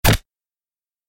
جلوه های صوتی
دانلود صدای تصادف 28 از ساعد نیوز با لینک مستقیم و کیفیت بالا